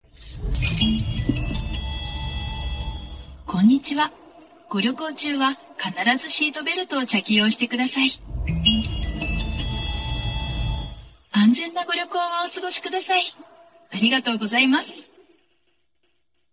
เสียง เชื่อมต่อ Carplay ภาษาญี่ปุ่น 🇯🇵🇯🇵🇯🇵
หมวดหมู่: เสียงเรียกเข้า
japanese-carplay-connection-sound-th-www_tiengdong_com.mp3